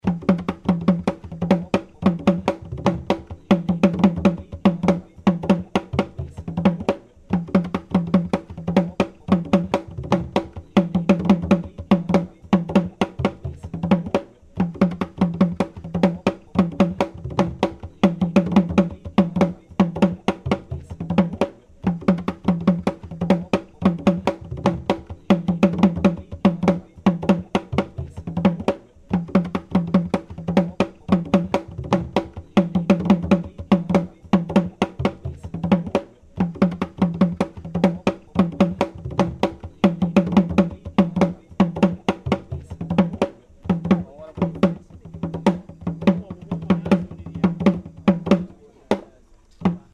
Asafo
The asafo drum is an open single-headed conical drum made of one single piece of wood.
The asafo drummer beats the drum alternately with a wooden stick and the palm of his hand. This drum has a loud bass tone with a long echo. It is highly suited to accompanying dances such as the ompeh dance.